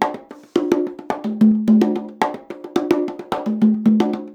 110 CONGA 1.wav